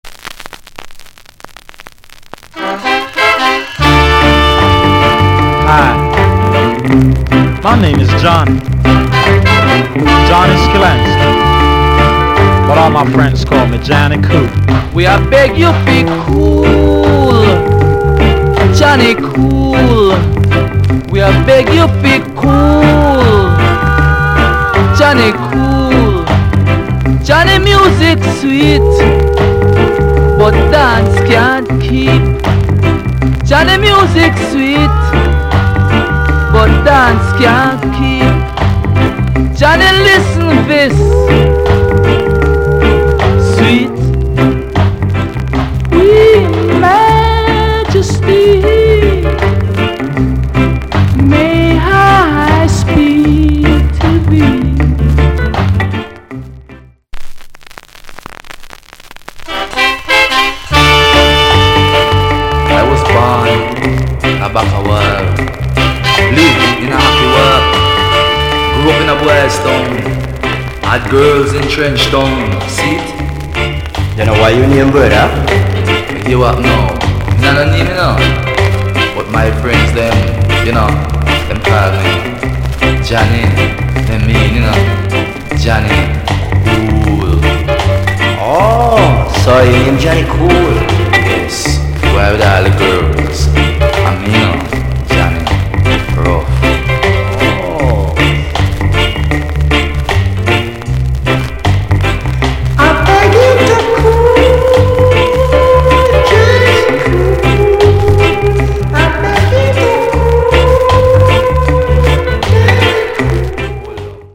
永遠のルード・ボーイ・チューン
細かな傷多し。